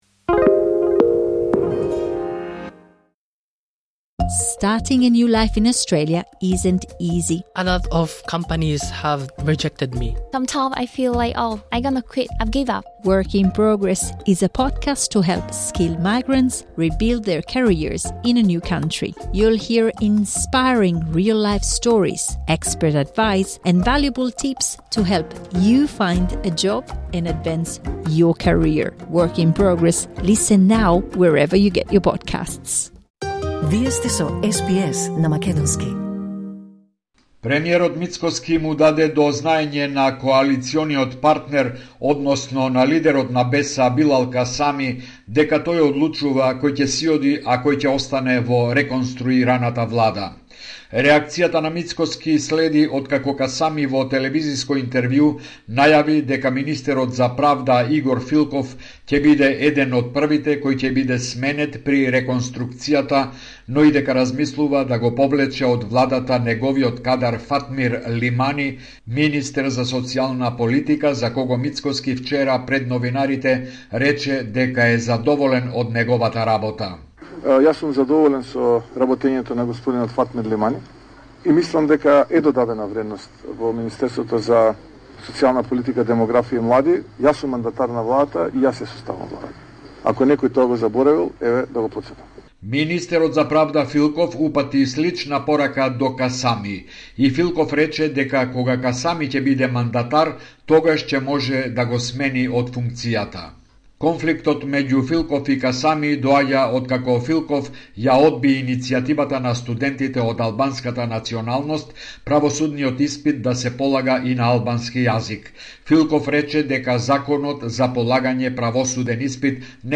Извештај од Македонија 13 февруари 2026